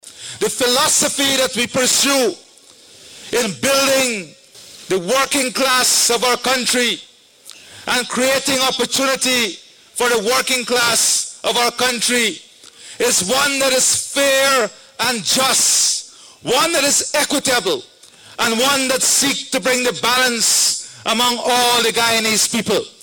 Addressing a large audience at State House, President Ali underscored the importance of creating a future where every family can thrive with dignity.
President-Labour-Day-Brunch-1.mp3